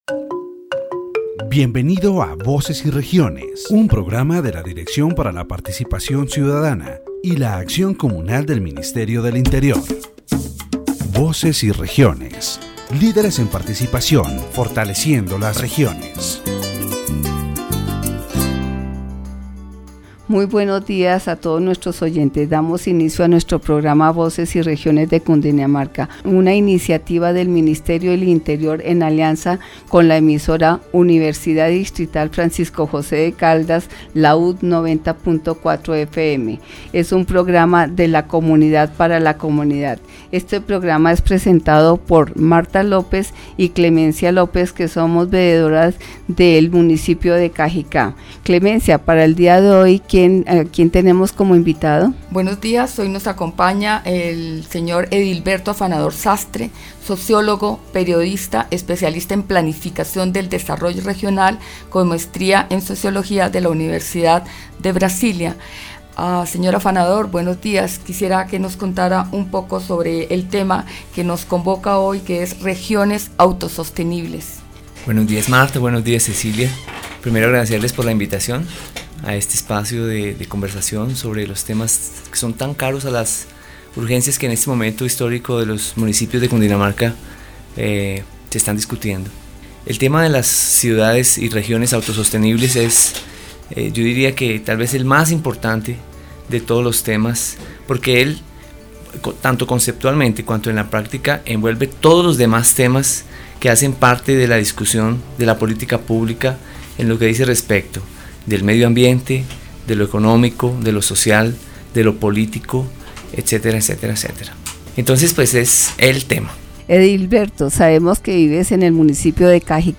The radio program "Voices and Regions" of the Directorate for Citizen Participation and Communal Action of the Ministry of the Interior focuses on the construction of self-sustaining regions in the Department of Cundinamarca.